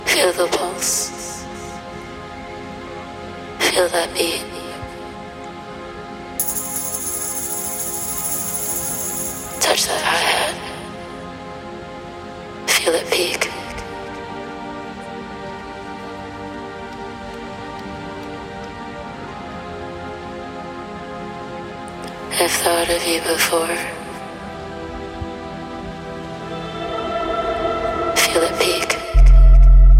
80's〜90'sフィーリングをセンス良く現代的に取り入れながらフロアを熱いエナジーで満たしていく大推薦盤です！